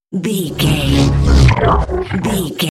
Sci fi alien energy pass by
Sound Effects
Atonal
futuristic
pass by